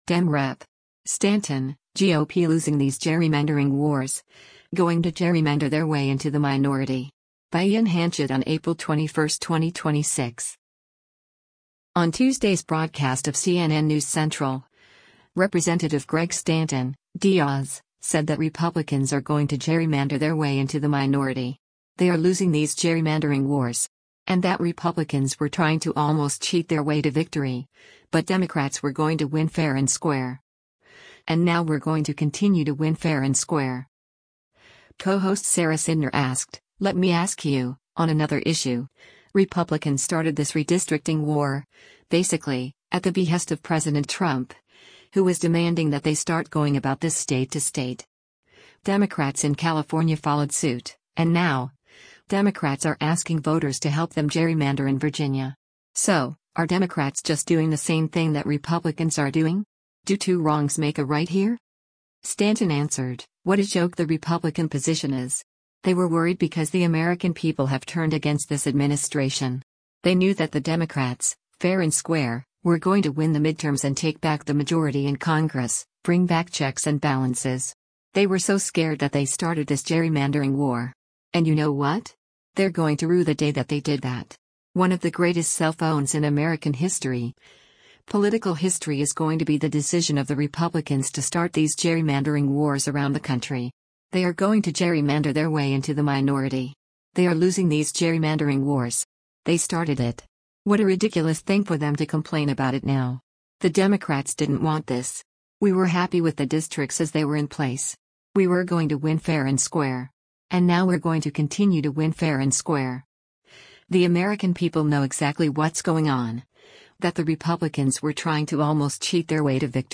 On Tuesday’s broadcast of “CNN News Central,” Rep. Greg Stanton (D-AZ) said that Republicans “are going to gerrymander their way into the minority. They are losing these gerrymandering wars.” And that “Republicans were trying to almost cheat their way to victory”, but Democrats “were going to win fair and square. And now we’re going to continue to win fair and square.”